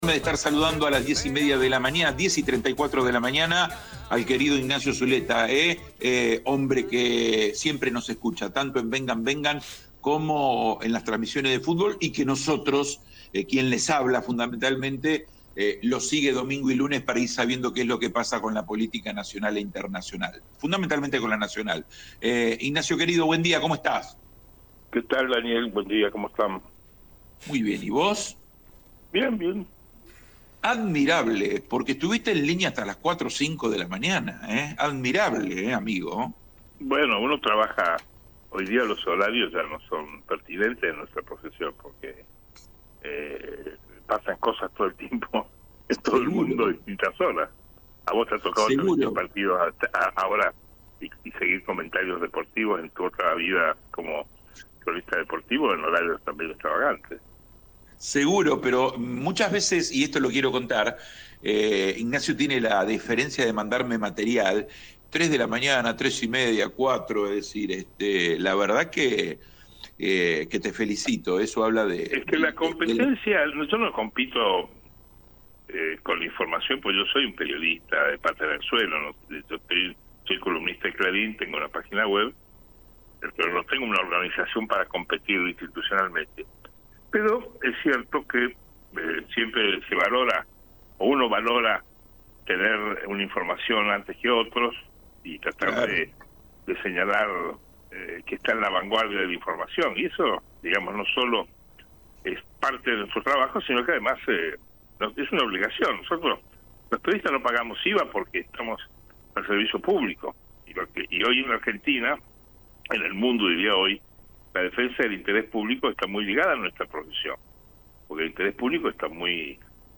Comparto un diálogo